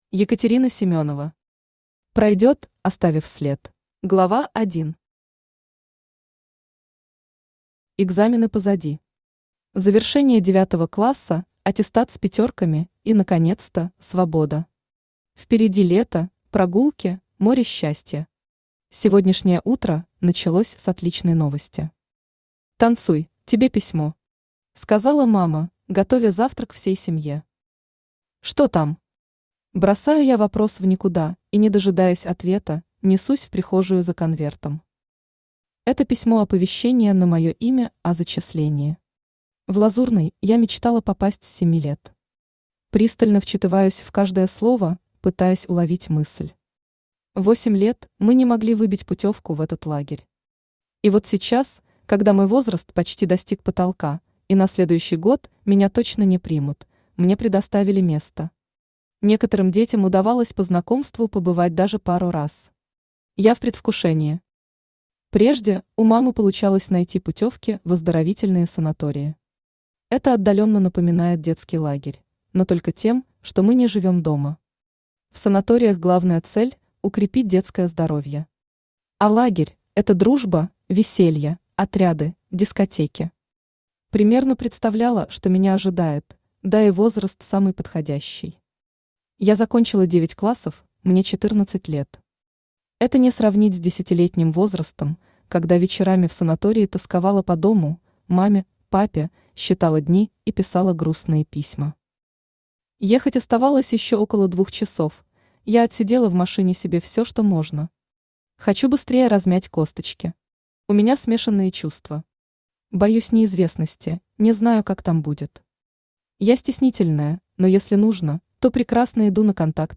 Читает: Авточтец ЛитРес
Аудиокнига «Пройдёт, оставив след».